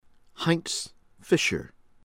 FERRERO-WALDNER, BENITA beh-NEE-tah   feh-REH-roh   WAHLD-nehr